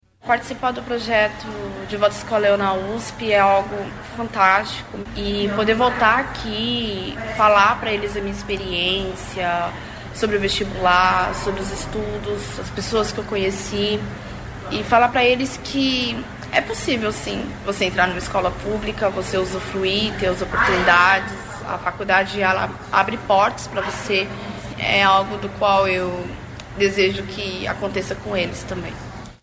O programa Cultura na USP, da Rádio USP, esteve em um destes reencontros durante a primeira edição do projeto, realizada em 2024.